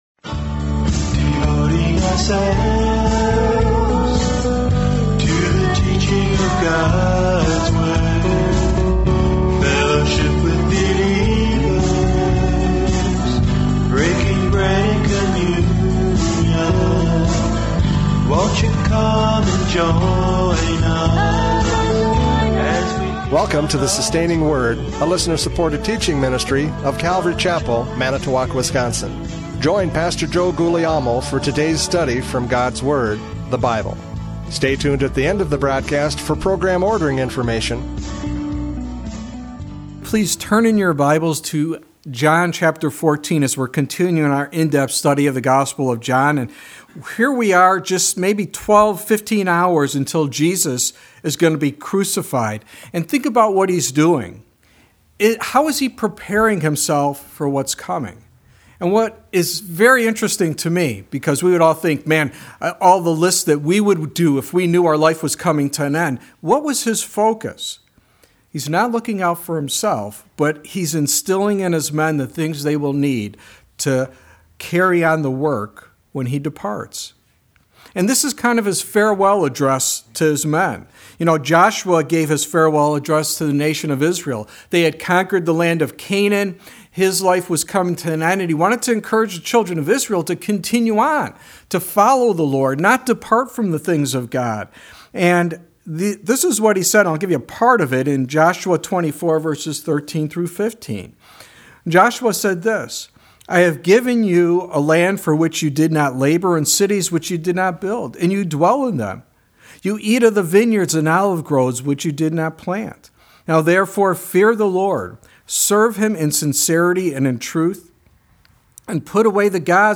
John 14:4-14 Service Type: Radio Programs « John 14:1-4 The Comfort of Christ!